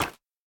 resin_brick_break.ogg